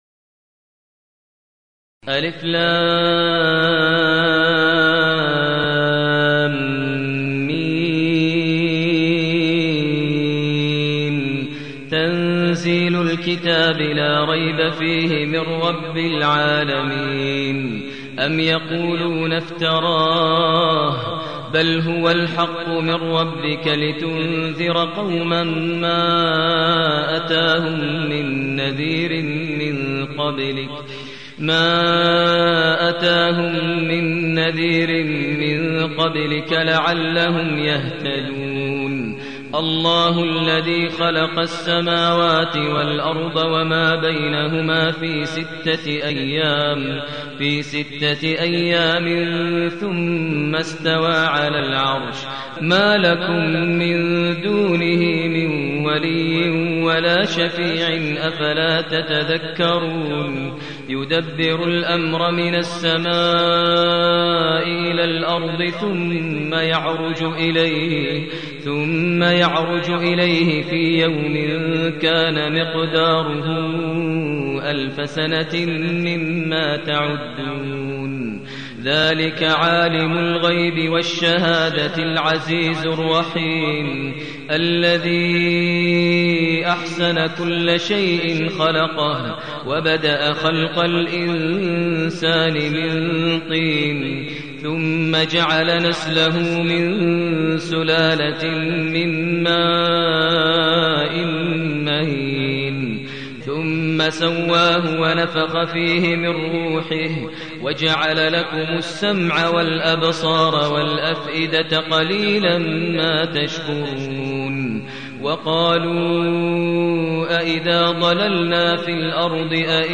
المكان: المسجد الحرام الشيخ: فضيلة الشيخ ماهر المعيقلي فضيلة الشيخ ماهر المعيقلي السجدة